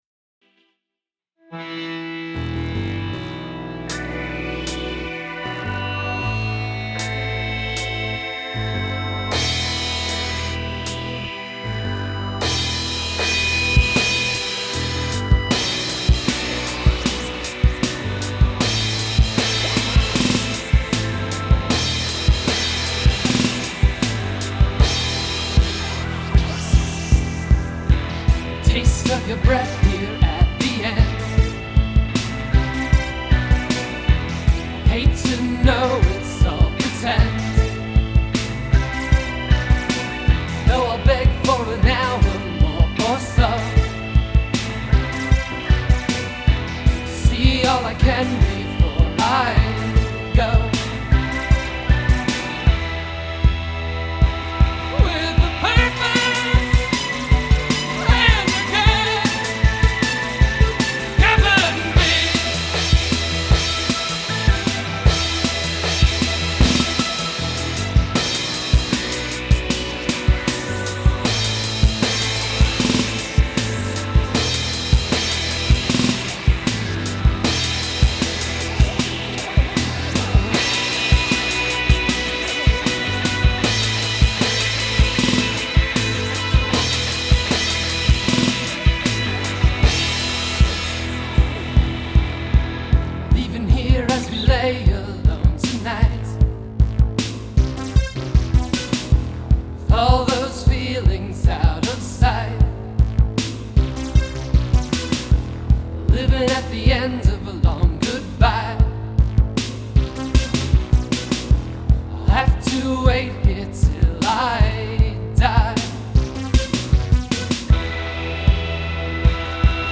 electronic/post-punk band